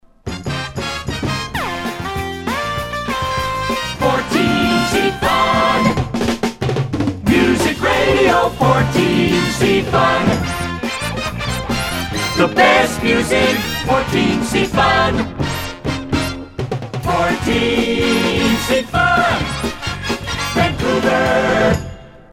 JINGLES